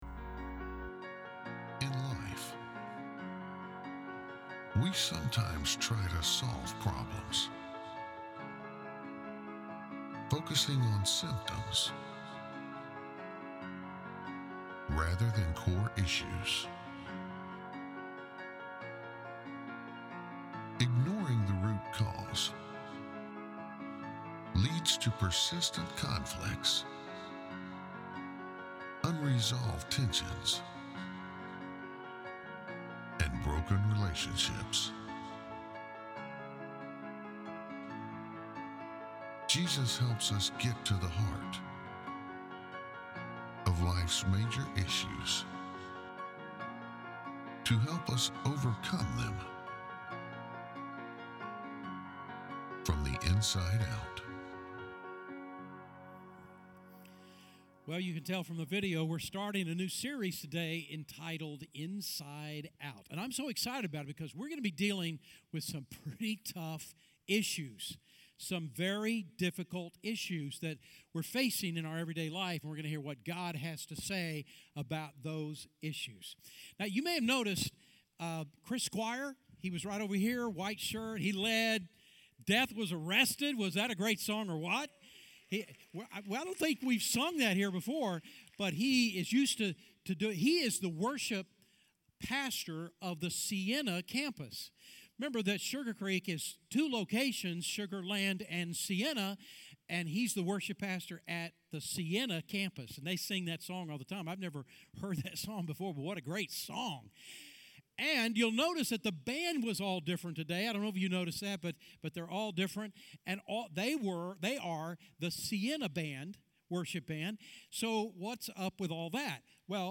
Here you can watch Sugar Creek sermons live online, or look back at previous sermons.